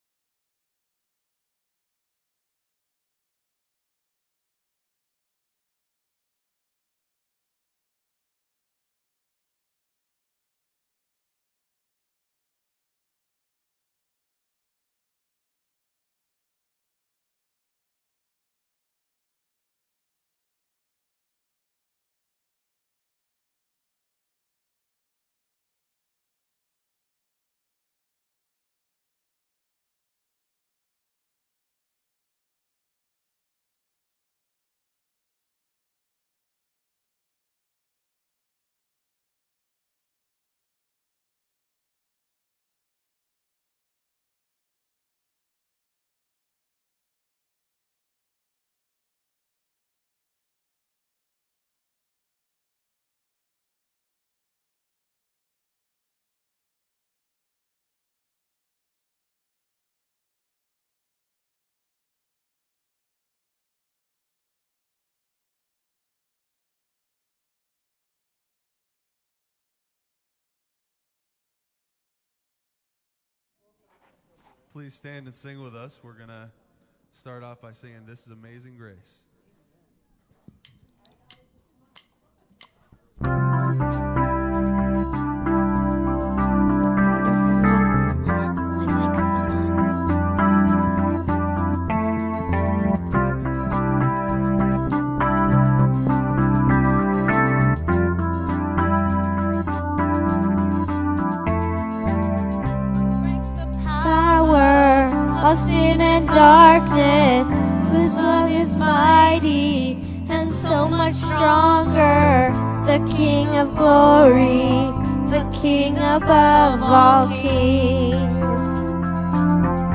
Worship on March 13th 2022
Praise-Audio-on-13-Mar-222-CD.mp3